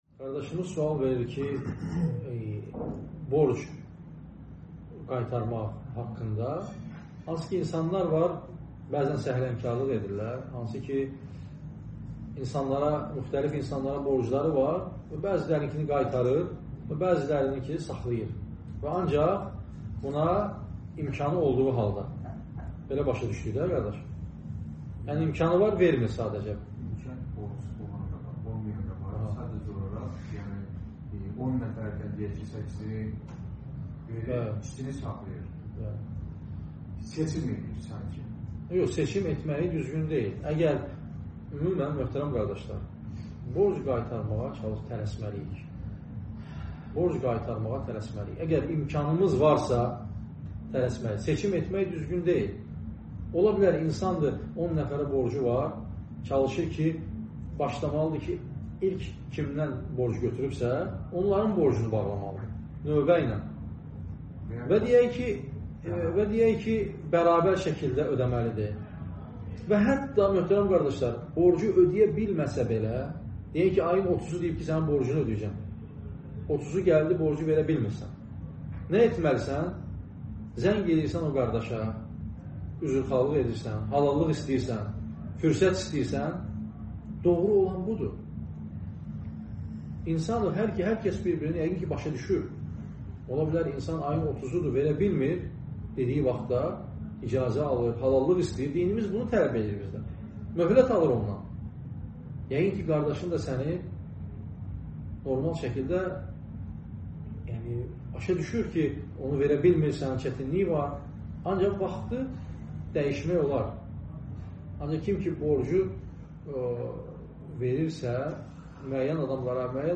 Sual-cavab (14.09.2024)